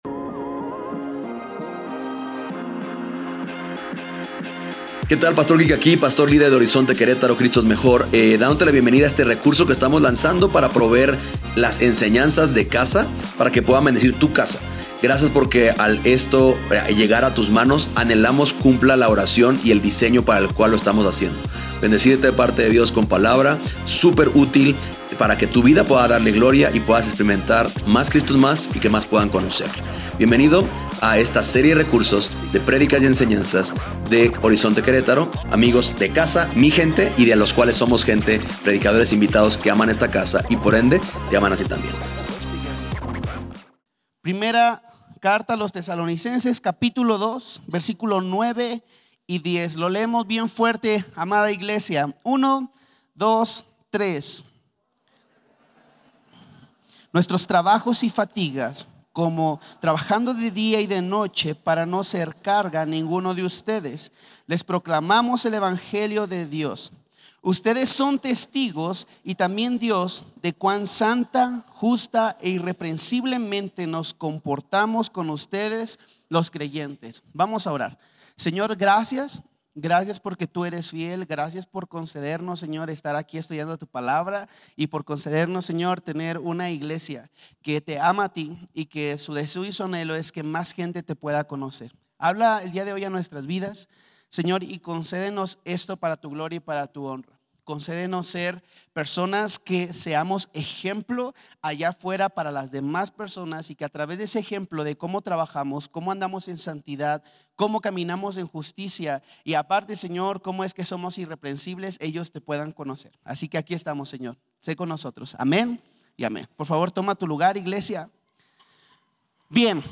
Este sermón nos invita a reflexionar sobre cómo estamos sirviendo y trabajando en la obra del Señor, siempre buscando la aprobación de Dios, no la de los hombres, y mostrando bondad y ternura a los demás.